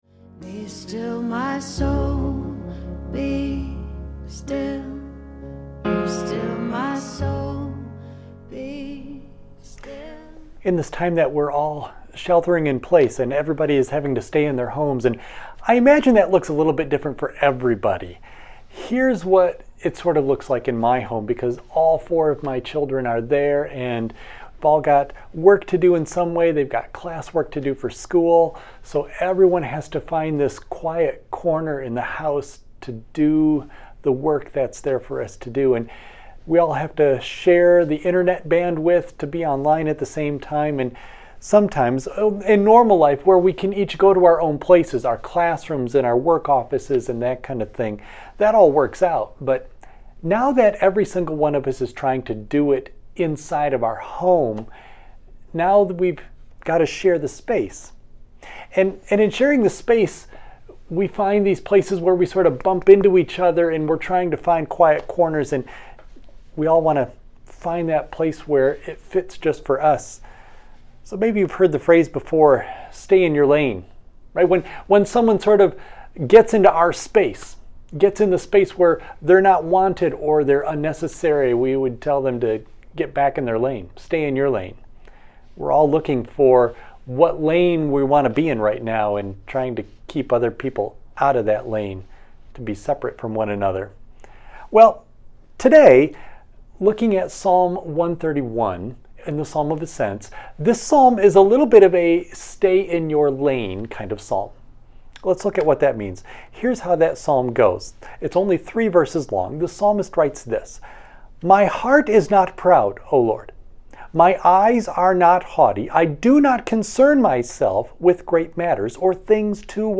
Audio only of message